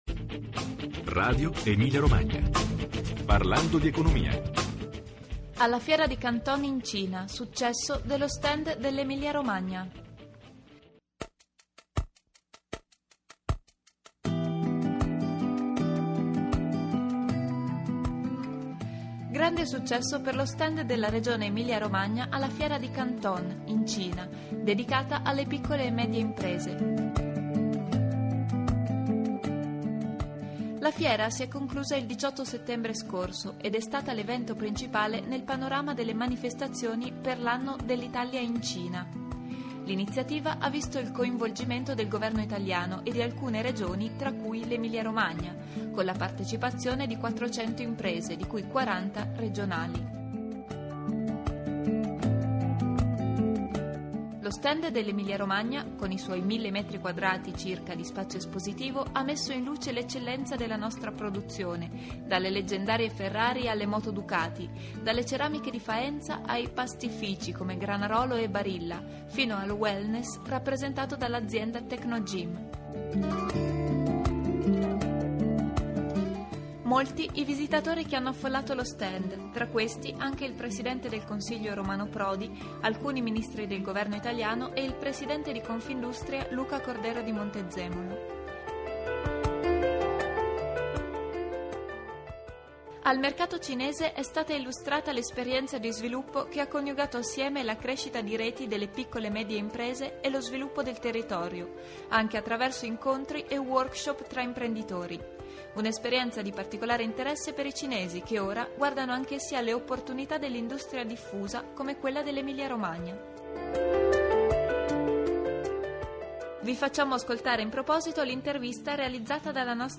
Alla fiera di Canton in Cina: successo dello stand dell'Emilia-Romagna. Intervista al presidente della Regione Vasco Errani, al presidente di Confindustria Luca Cordero di Montezemolo